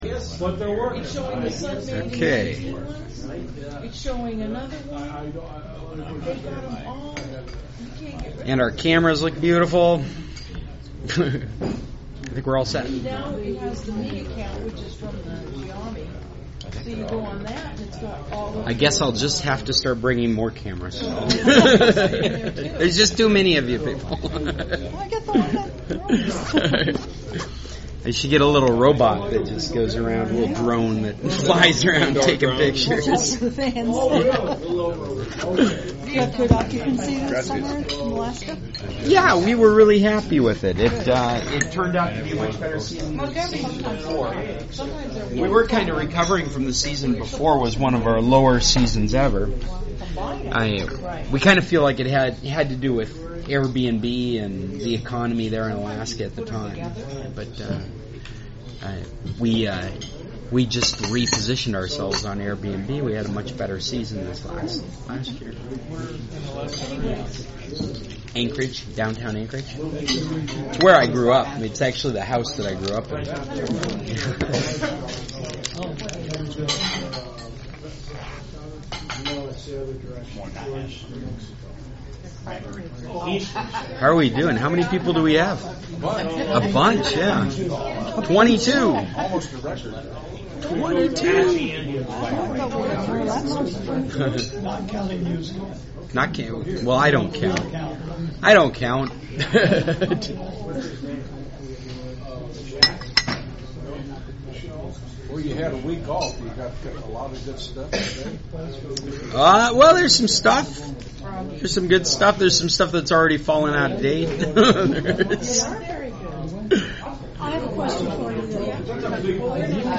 Today's meeting had at least 24 people and a variety of topics.